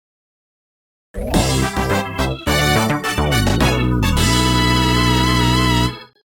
The fanfare for beating a Missions mode boss